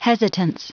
Prononciation du mot hesitance en anglais (fichier audio)
Prononciation du mot : hesitance